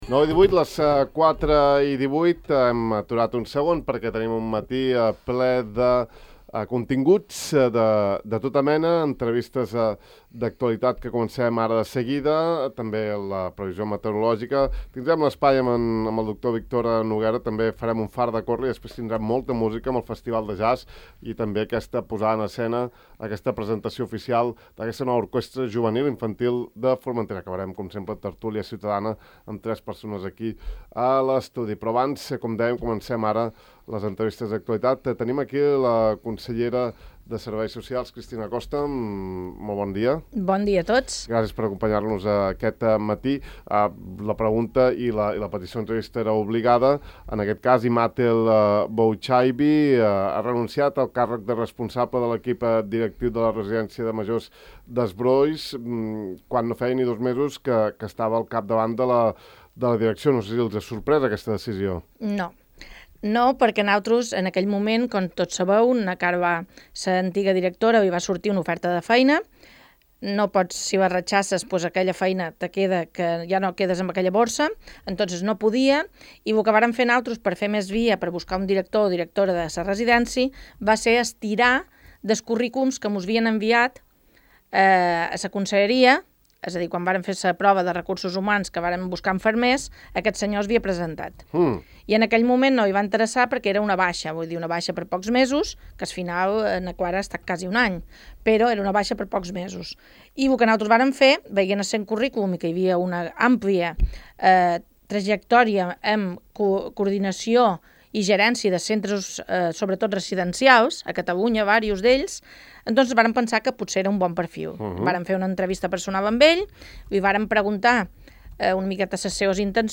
La consellera insular de Benestar Social, Cristina Costa, ha visitat Ràdio Illa per abordar diversos temes del seu àmbit d’actuació.